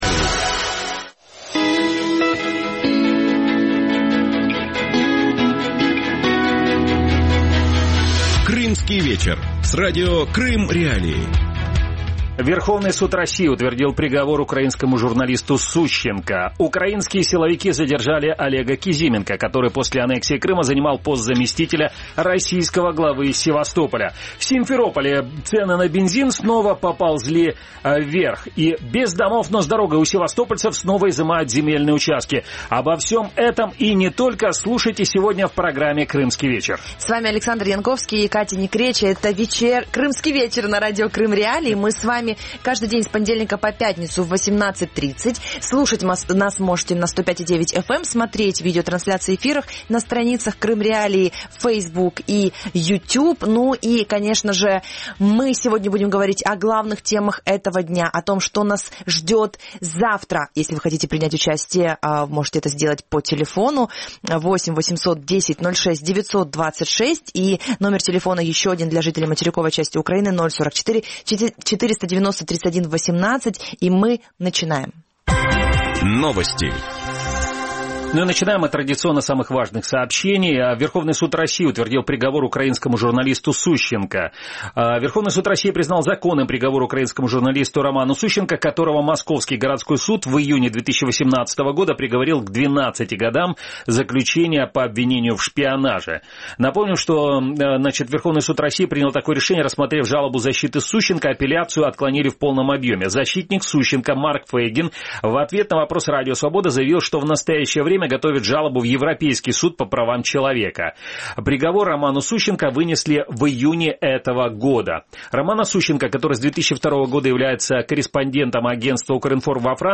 ток-шоу «Крымский вечер
«Крымский вечер» – шоу, которое выходит в эфир на Радио Крым.Реалии в будни с 18:30 до 19:30.